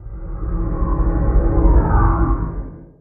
Minecraft Version Minecraft Version 25w18a Latest Release | Latest Snapshot 25w18a / assets / minecraft / sounds / mob / guardian / elder_idle2.ogg Compare With Compare With Latest Release | Latest Snapshot
elder_idle2.ogg